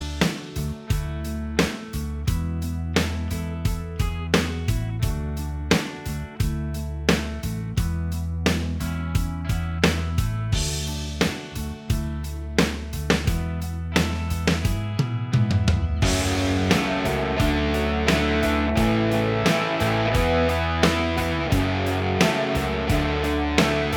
Minus All Guitars Pop (1990s) 4:19 Buy £1.50